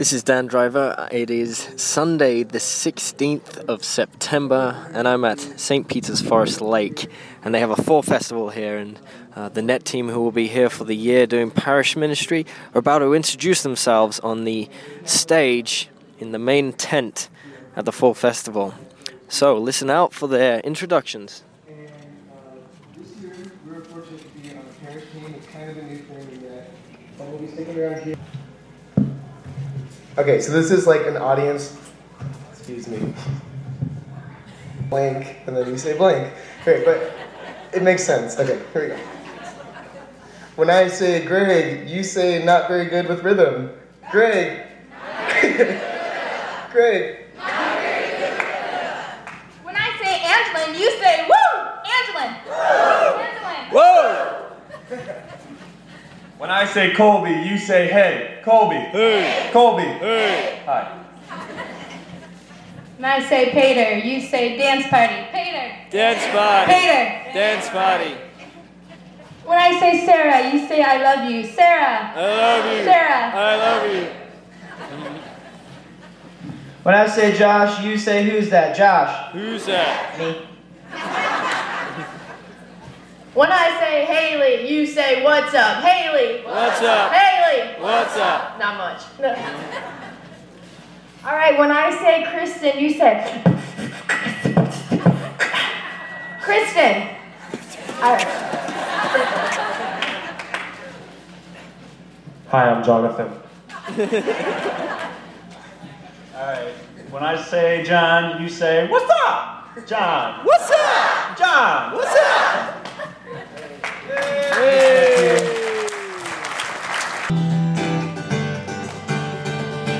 The Official Intro of the Forest Lake Parish Team at St. Peters Catholic Church Fall Festival!